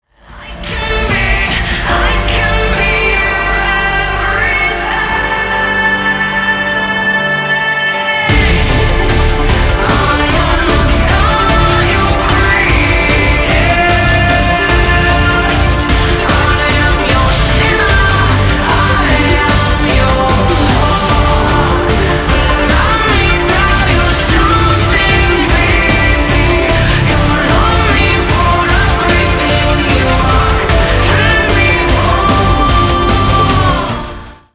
громкие
женский вокал
Industrial rock
Electronic Rock
Synth Rock
альтернативный метал